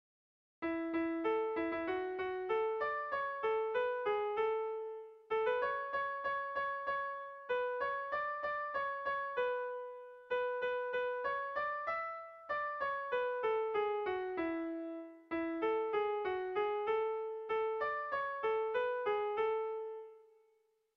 Erlijiozkoa
Zortziko txikia (hg) / Lau puntuko txikia (ip)
ABDA